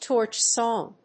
アクセントtórch sòng